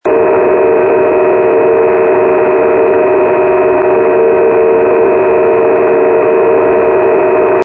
Стоит сетка несущих модулированных - Звук в SSB (USB)